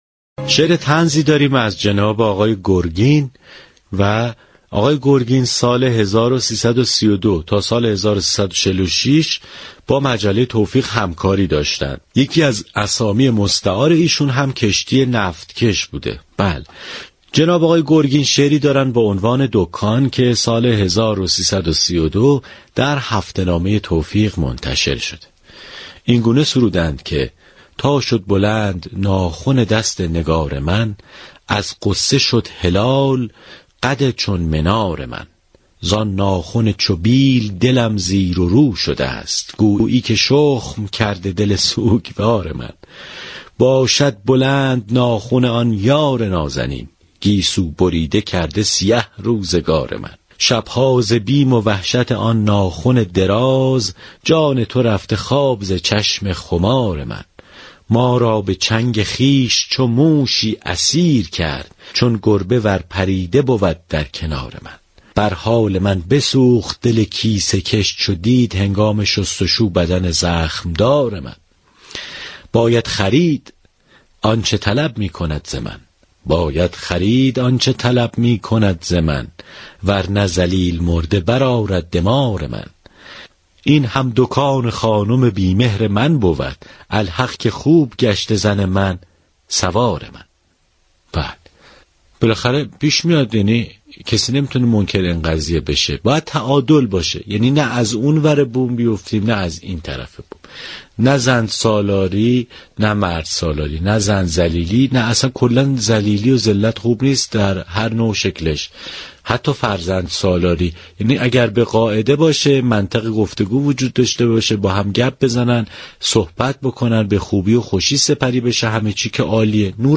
شعر طنز